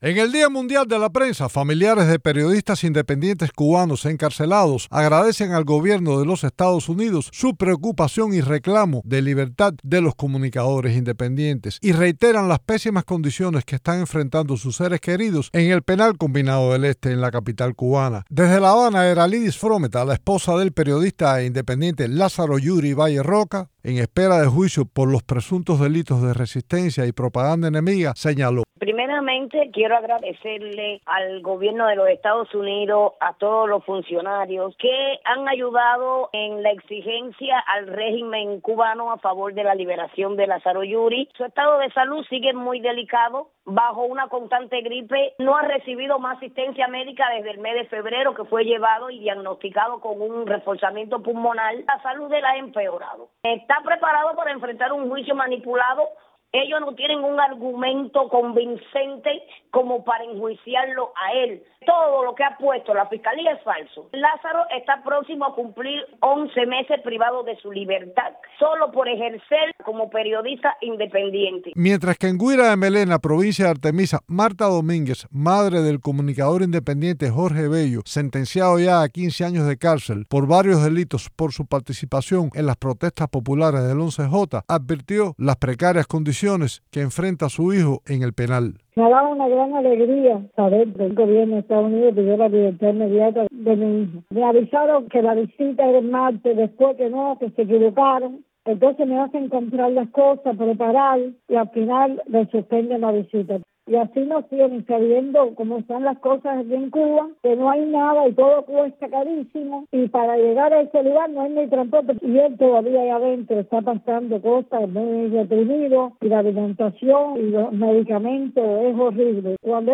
entrevistó a familiares de los periodistas encarcelados